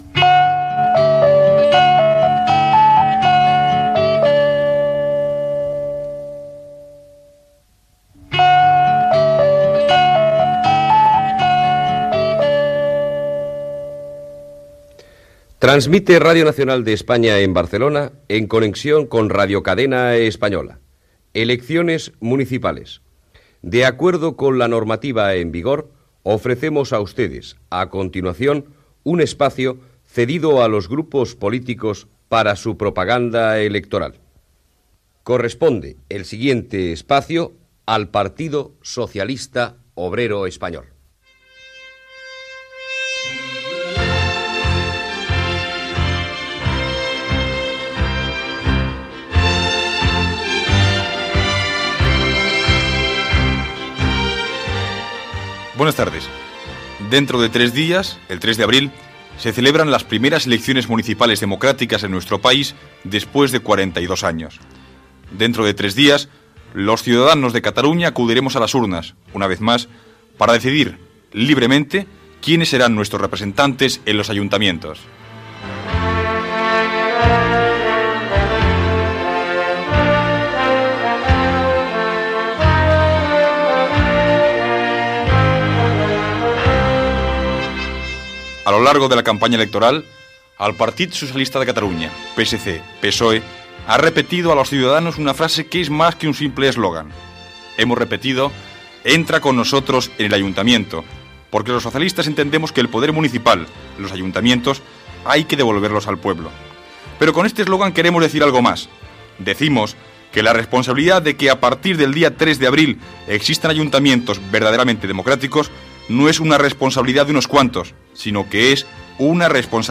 Sintona de l'emisssora, identificació, careta del programa i bloc electoral del Partido Socialista Obrero Español, amb paraules de Narcís Serra, candidat del Partit Socialista de Catalunya a l'Ajuntament de Barcelona